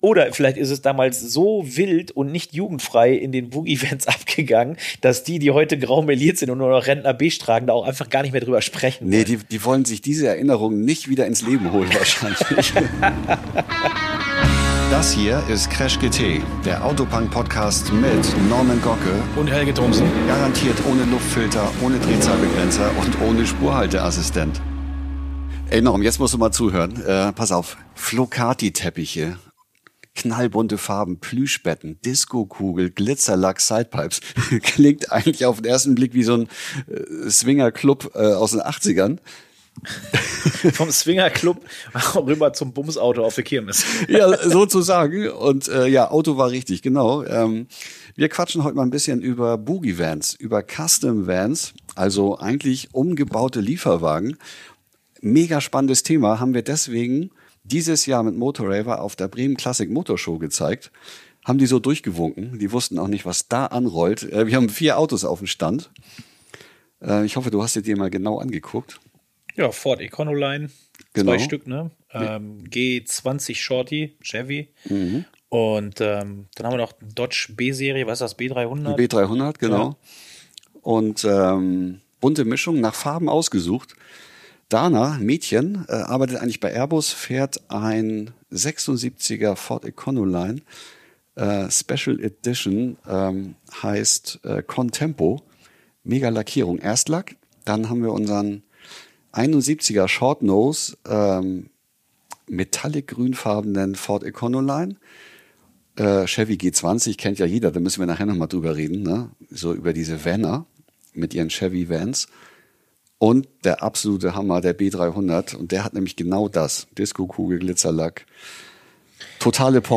Aufgezeichnet auf der Bremen Classic Motorshow 2026, wo Motoraver genau diesen Boogie Vans ein eigenes Thema gewidmet hat.